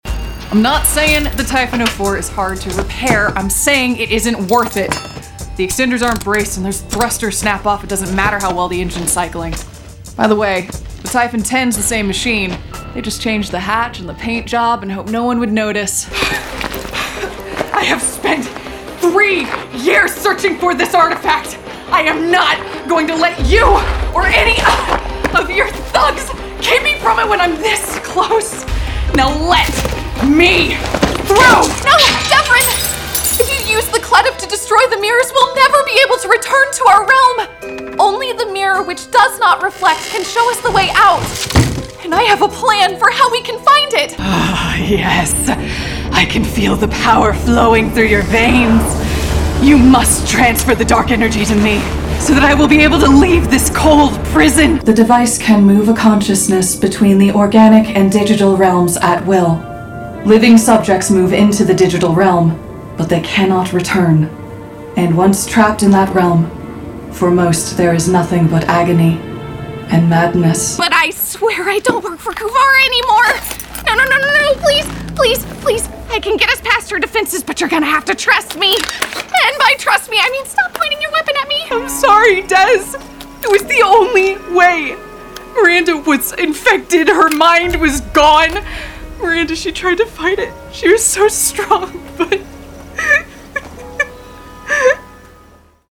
Voice Actor
Bright!
Warm, textured, genuine,